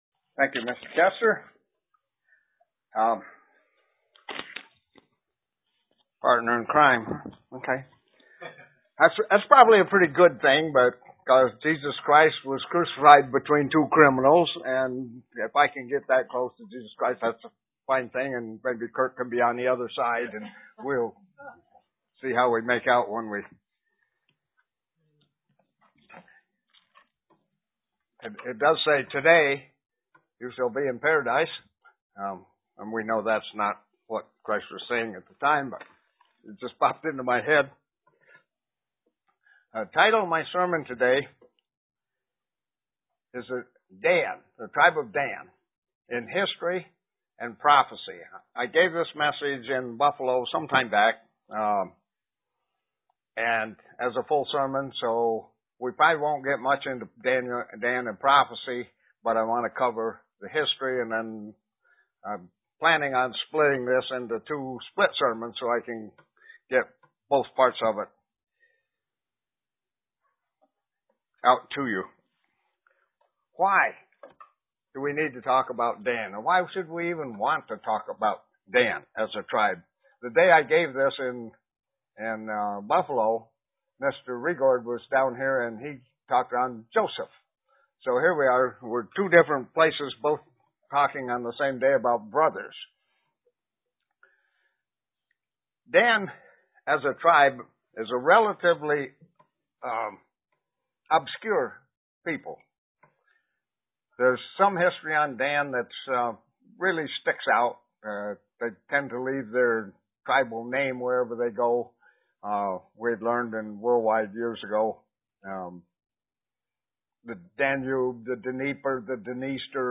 Print The history of the Tribe of Dan UCG Sermon Studying the bible?
Given in Elmira, NY